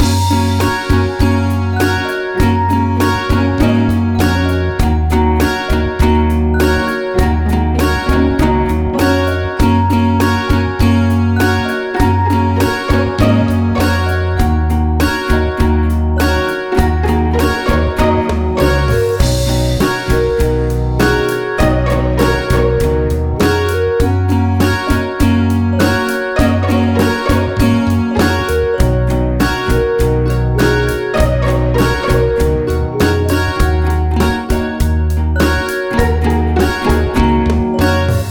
Music
funny